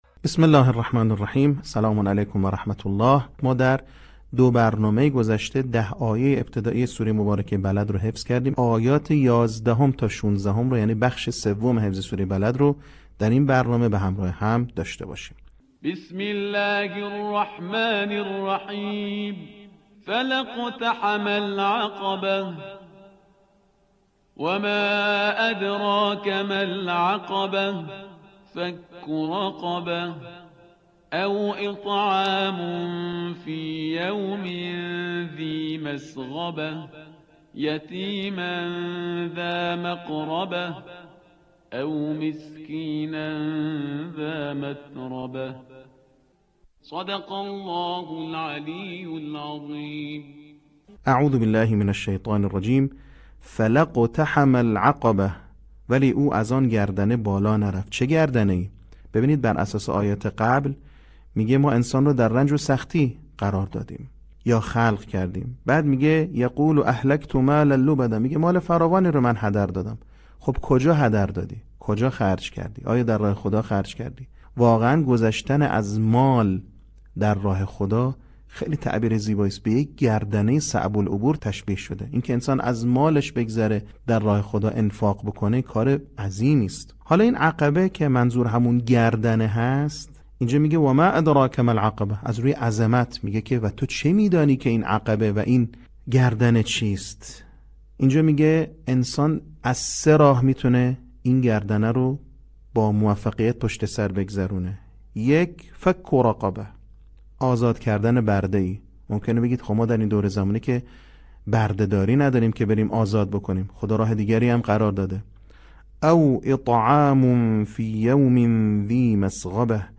صوت | بخش سوم آموزش حفظ سوره بلد
آموزش قرآن